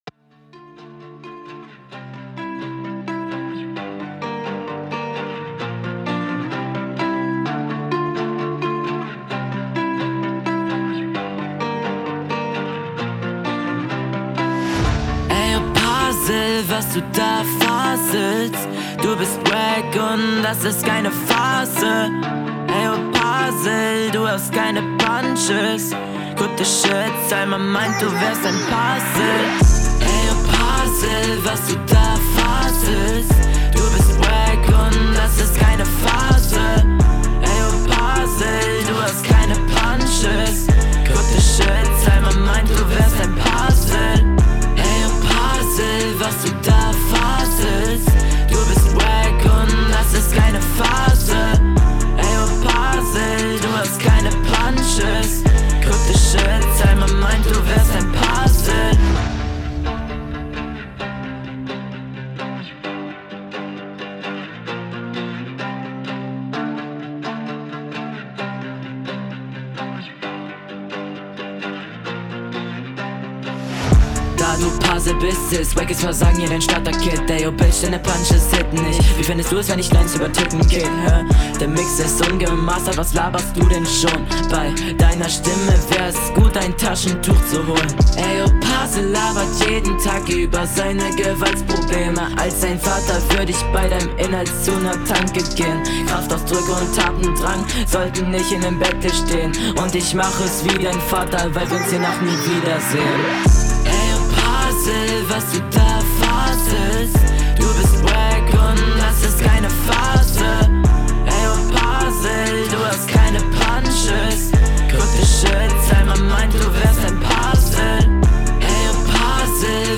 Schöne Runde auch wenn die Hook zu lang ist und grundsätzlich nicht meinem Geschmack entspricht.
Mega Beat und toll dass du so einen pickst.